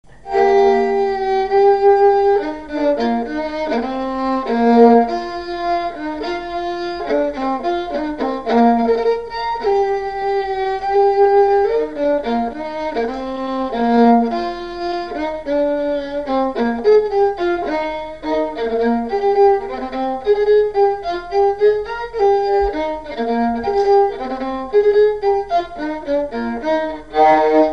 Mareuil-sur-Lay
Usage d'après l'informateur gestuel : danse
Pièce musicale inédite